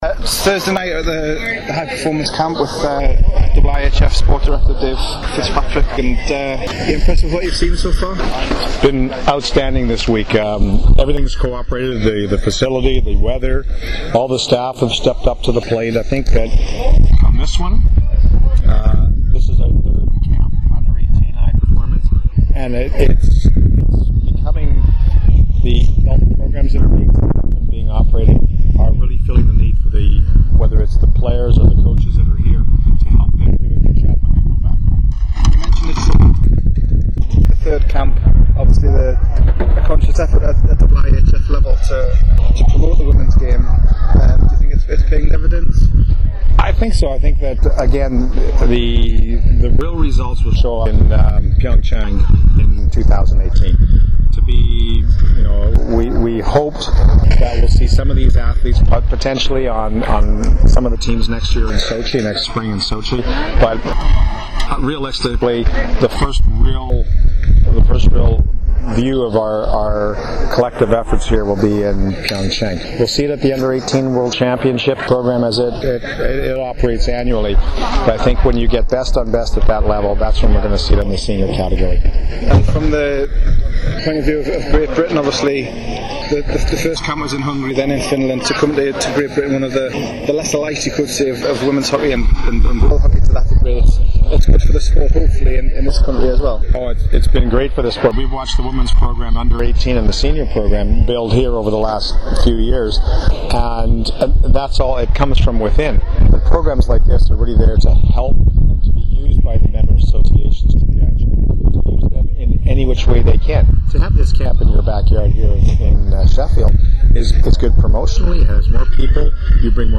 At the IIHF High Performance Camp in Sheffield